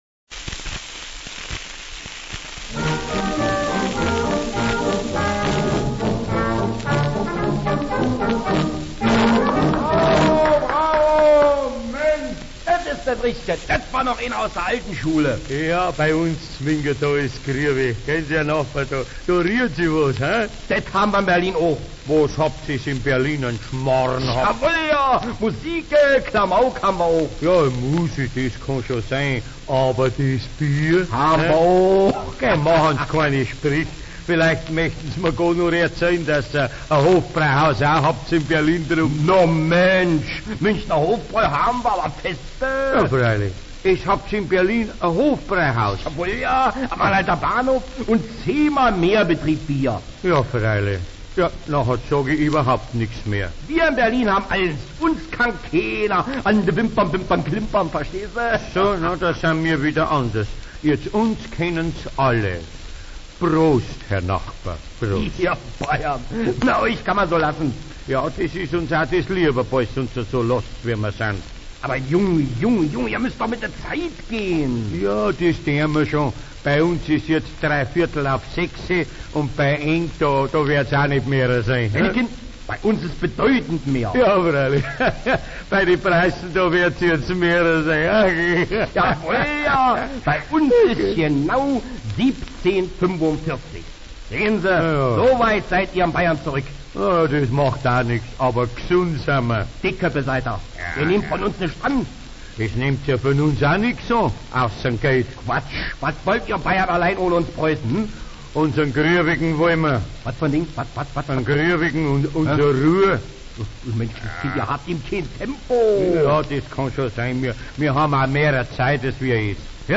Nord und Süd im Hofbräuhaus - eine humoristische Szene.